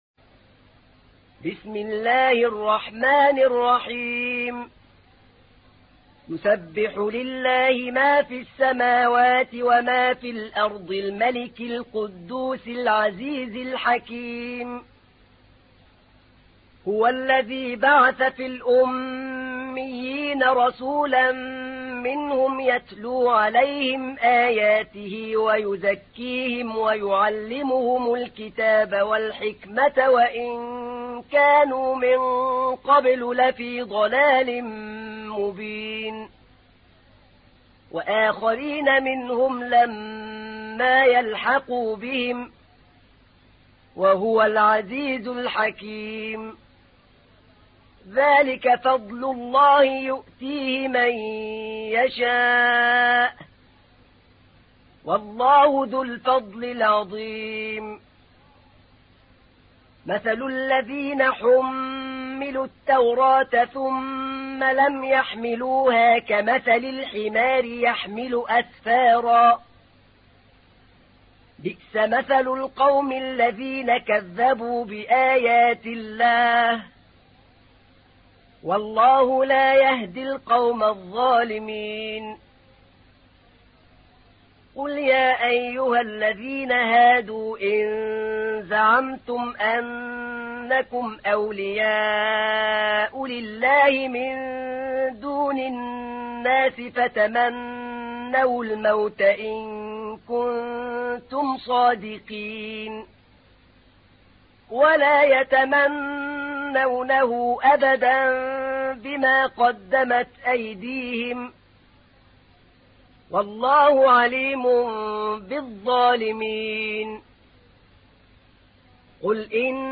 تحميل : 62. سورة الجمعة / القارئ أحمد نعينع / القرآن الكريم / موقع يا حسين